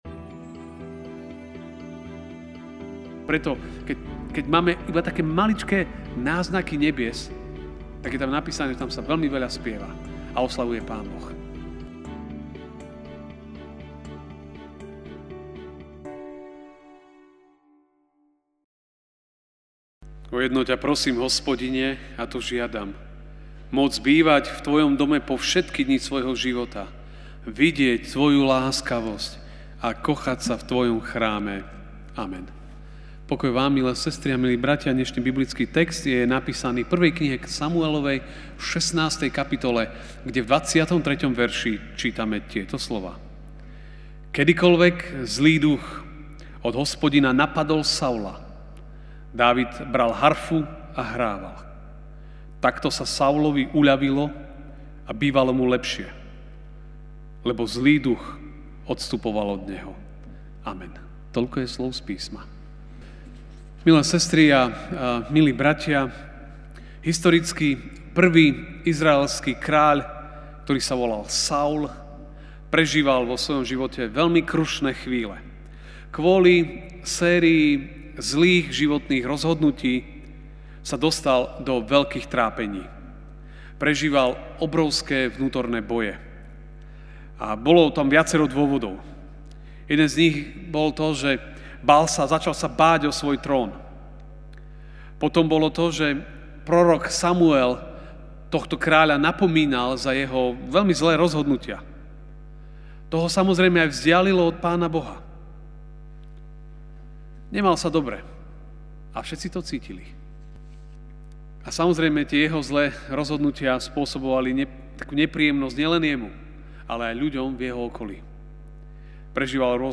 sep 15, 2019 Chváliť Pána MP3 SUBSCRIBE on iTunes(Podcast) Notes Sermons in this Series Večerná kázeň: Chváliť Pána (1S 16, 23) Kedykoľvek zlý duch od Boha napadol Saula, Dávid bral harfu a hrával.